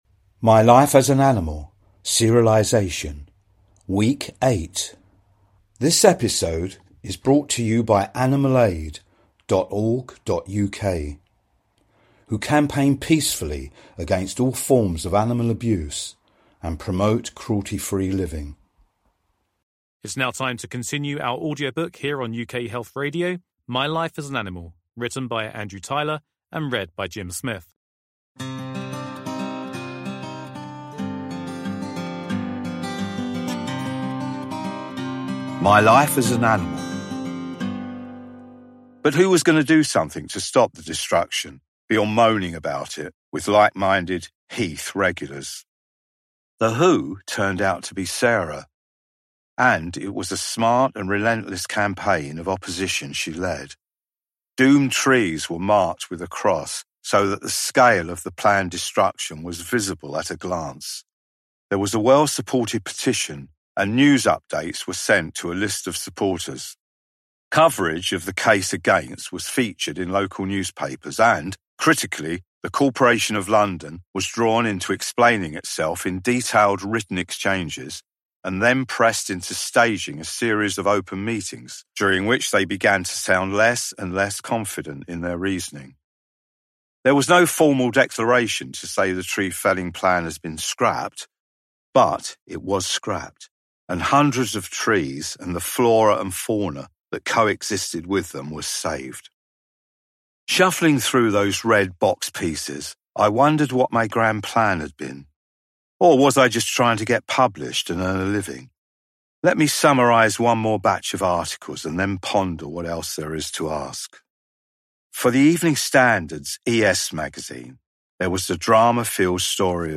UK Health Radio is running its first ever Book serialisation!
It is beautifully written and sensitively voiced.